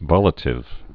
(vŏlĭ-tĭv)